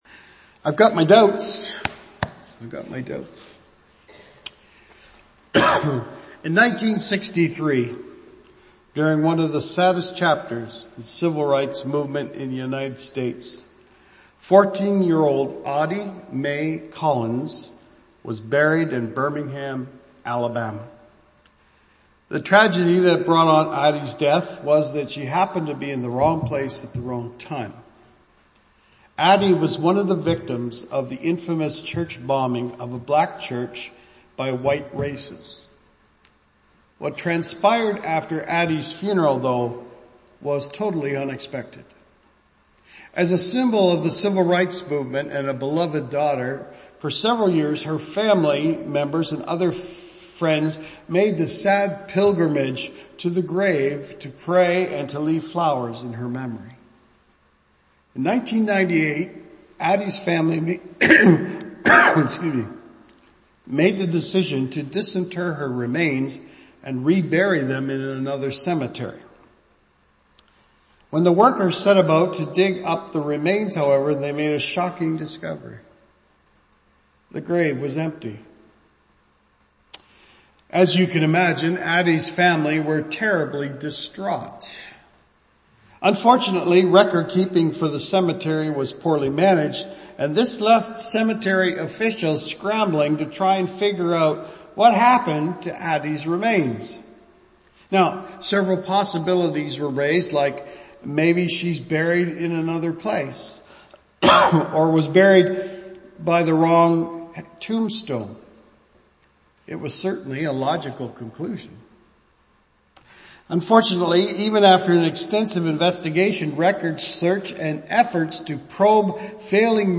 First Baptist Church of Simcoe
Archived Sermons